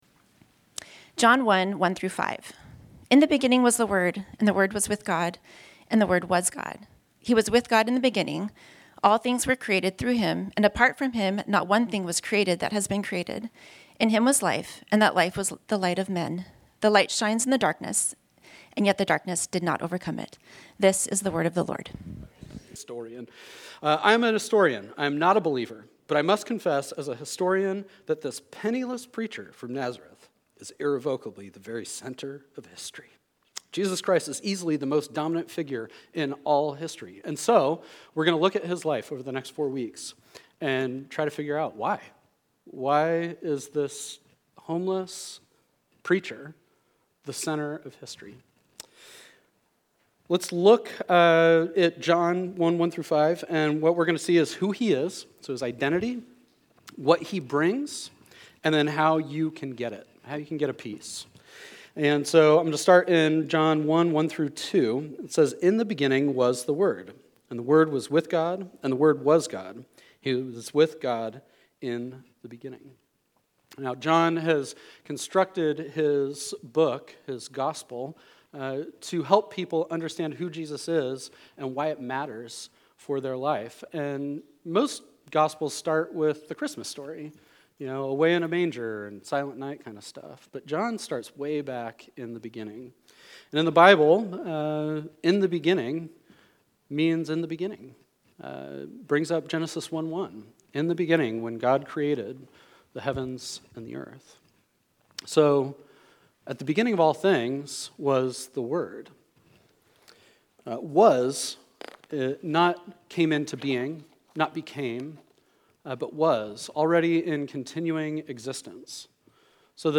This sermon was originally preached on Sunday, December 7, 2025